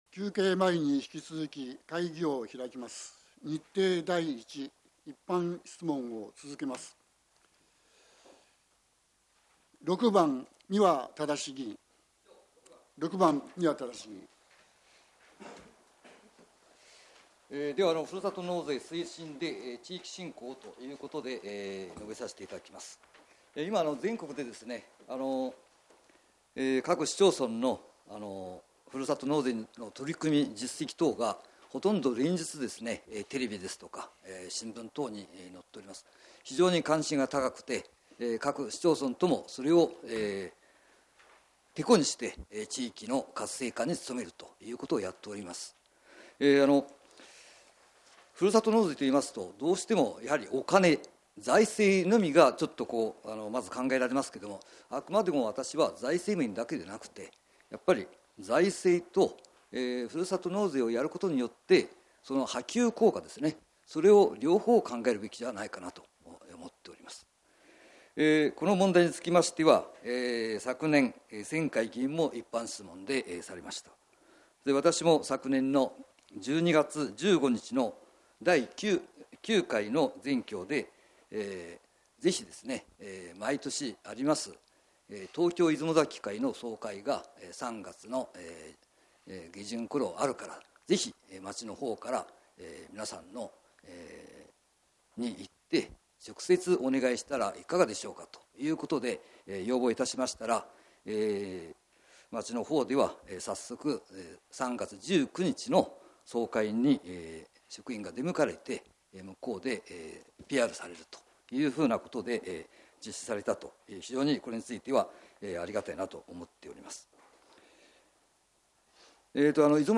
平成28年3月定例会 2日目（一般質問） | 出雲崎町ホームページ